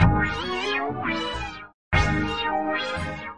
描述：Cd切割，从CD上切下，并用Sawcutter 1,2或其它切割，用FX处理，标准化。